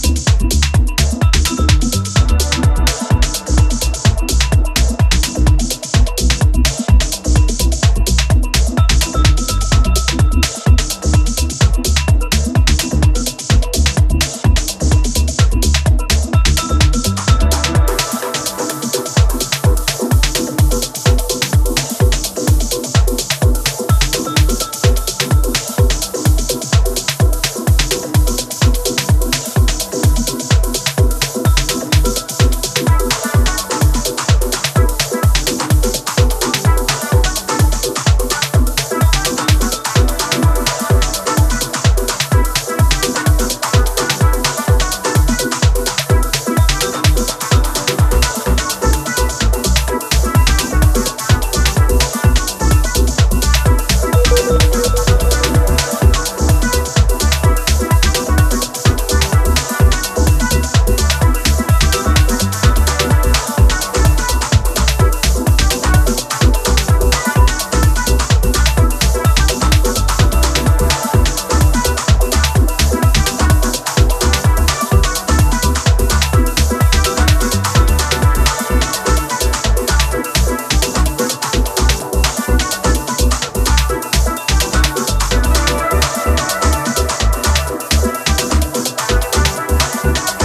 アクアティックなテクスチャーを帯びた